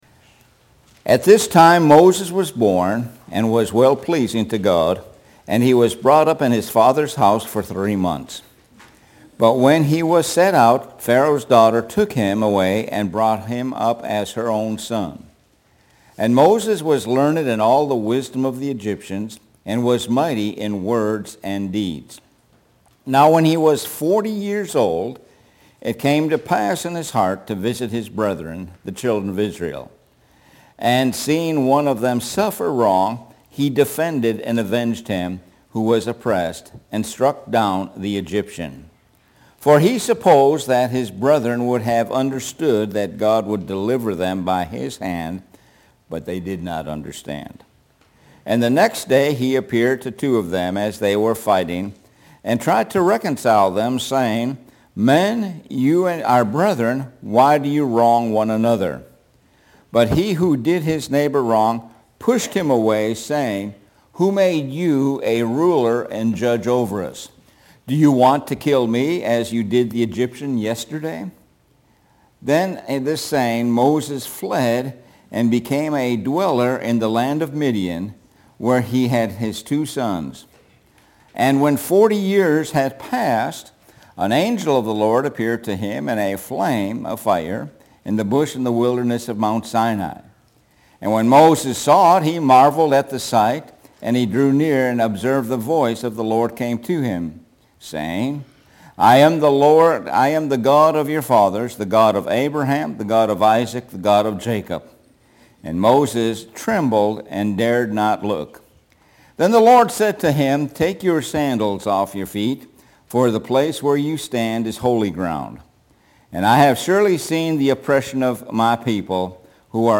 Sun AM Sermon